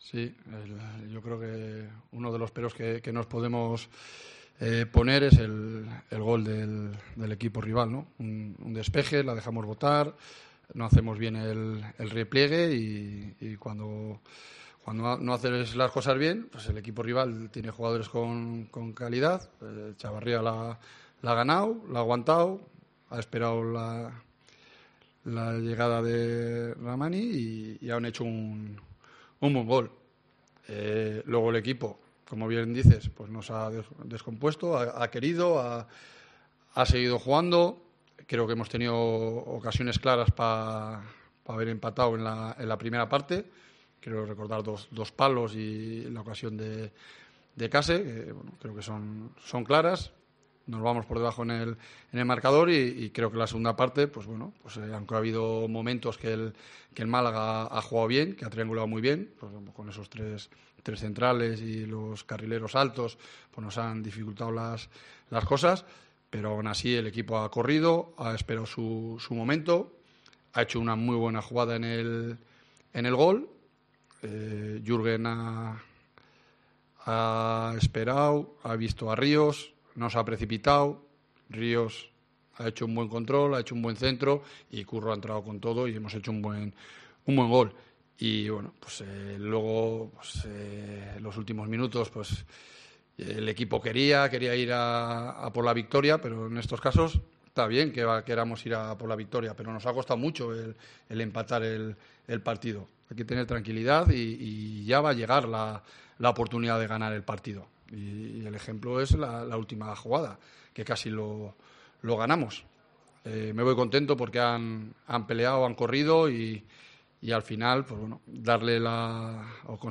AUDIO: Escucha aquí las palabras del míster de la Deportiva tras el empate (1-1) en casa, en el estadio El Toralín, ante el Málaga